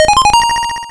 RedCoin3.wav